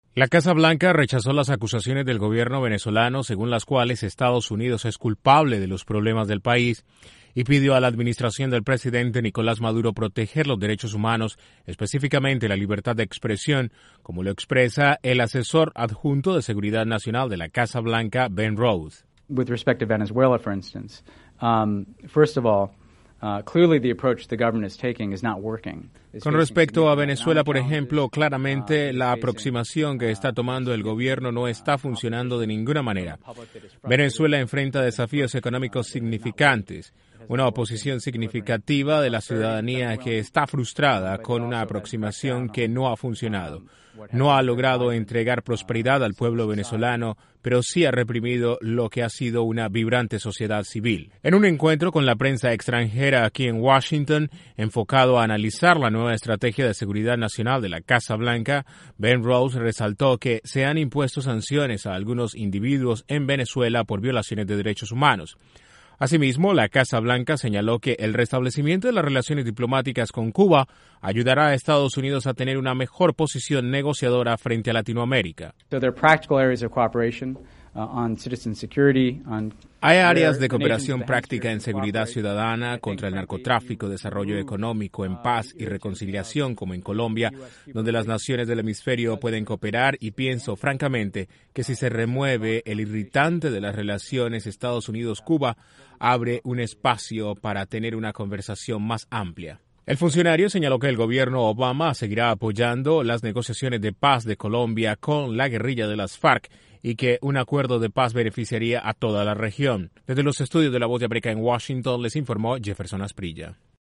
EE.UU. pidió respeto por los derechos humanos en Venezuela y señaló que acercamientos con Cuba ayudarán a Washington a obtener un mejor pie negociador con Latinoamérica. Desde la Voz de América en Washington informa